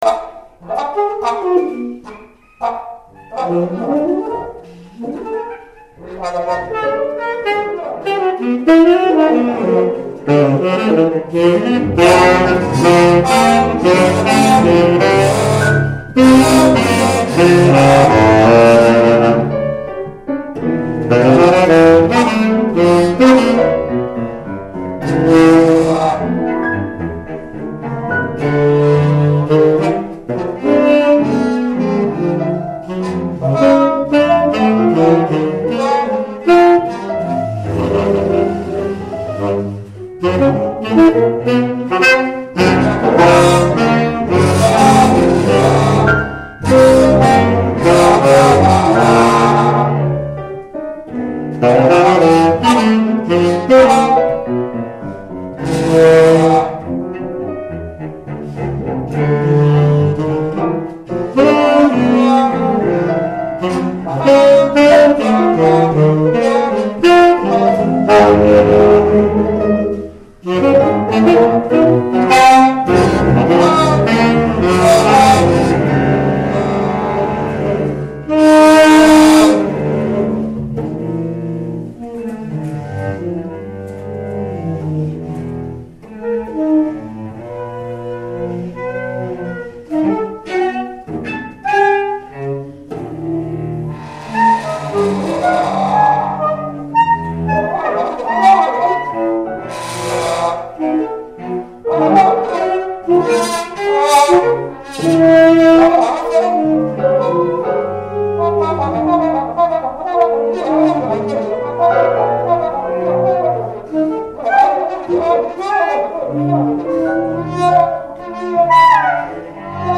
Premonition is one of the more written out compositions that this quartet played.
trombone
saxophone
cello
piano